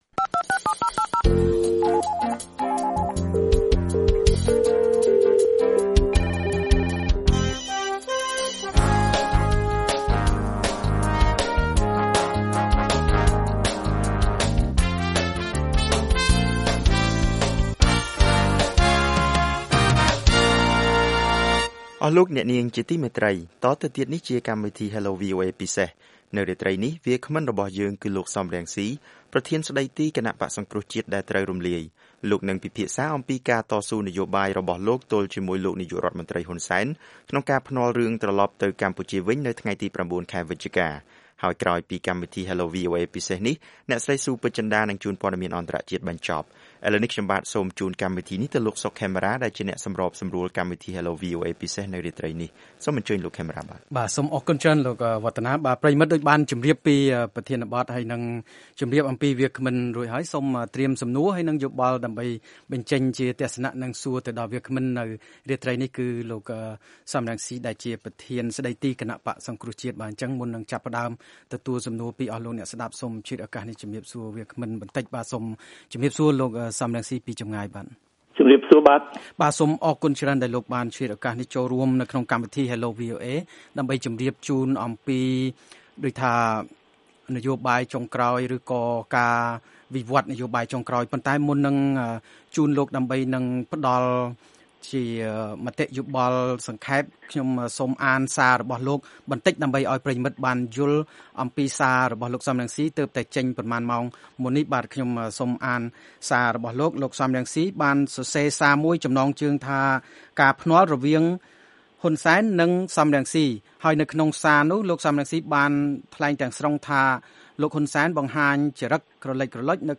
លោក សម រង្ស៊ី ថ្លែងដូច្នេះក្នុងពេលលោកបានចូលរួមជាវាគ្មិននៃកម្មវិធី Hello VOA ពិសេសកាលពីថ្ងៃសុក្រ ទី៣០ ខែសីហា ឆ្នាំ២០១៩៕